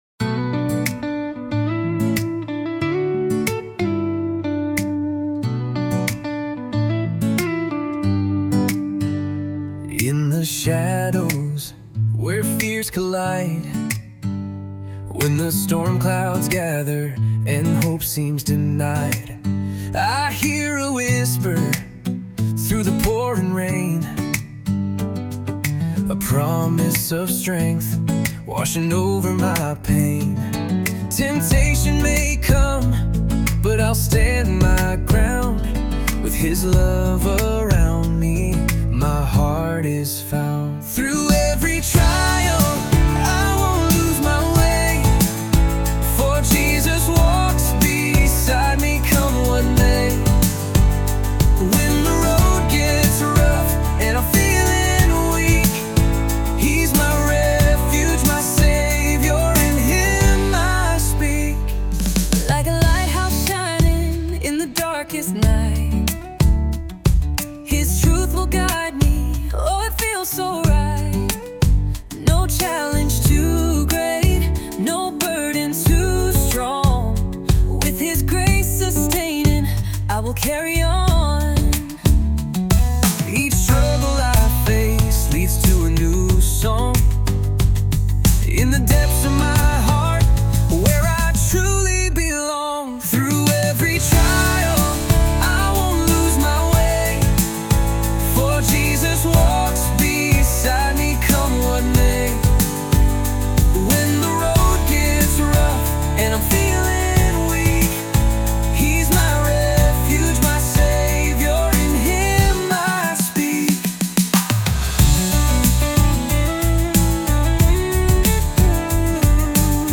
Christian Music